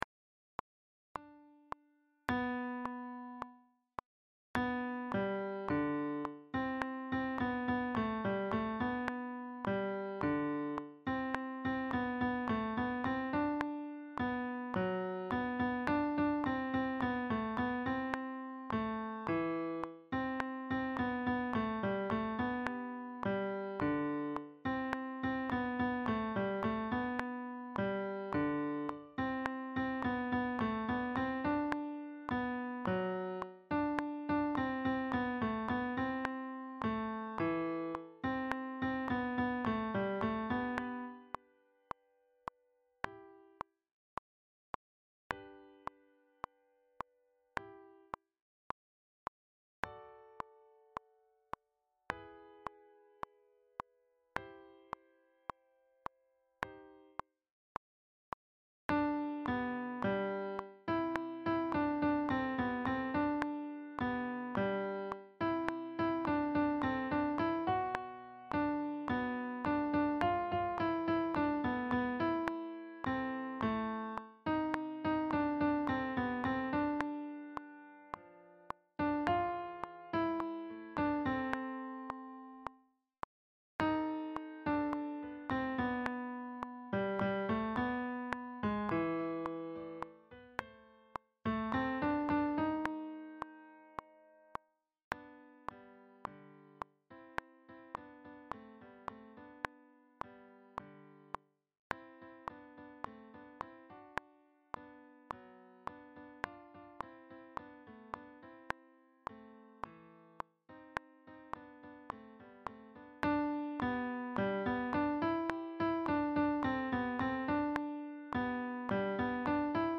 Coeur de Soldat ténor
Coeur_de_soldat_Tenor.mp3